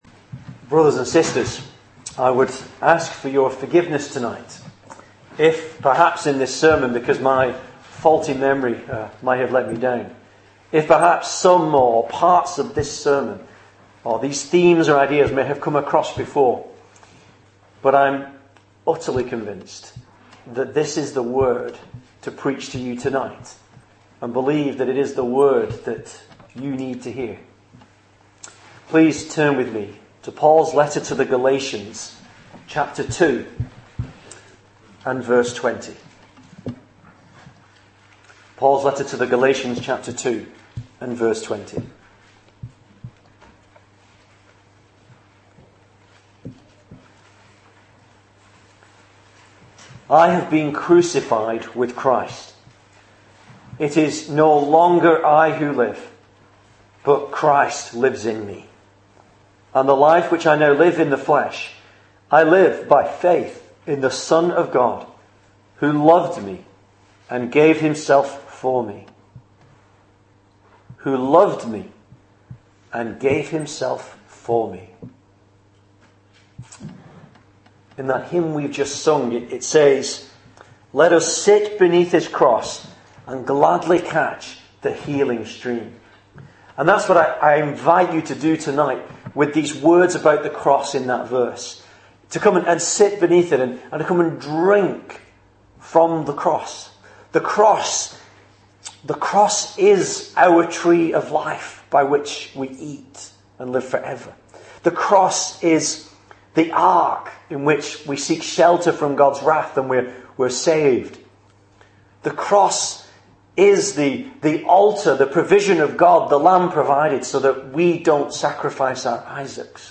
Series: Christmas Sermons